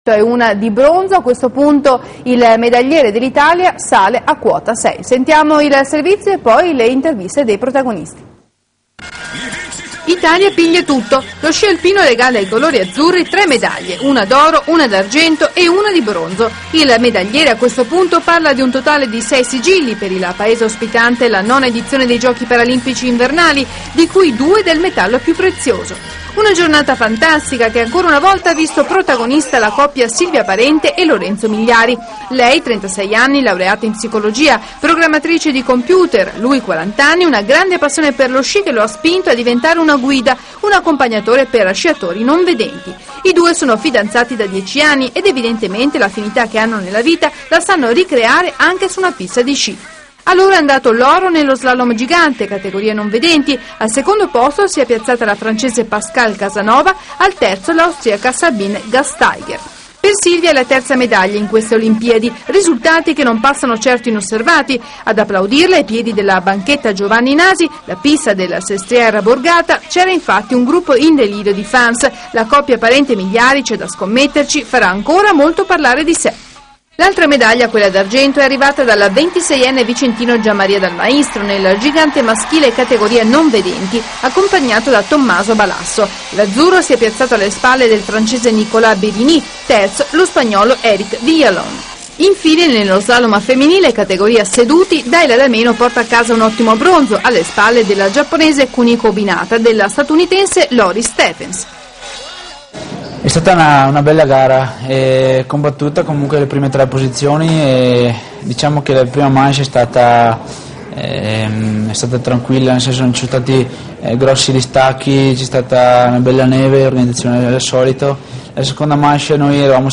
servizio del TG di Sportitalia della sera.
17marzoTGSportitalia.mp3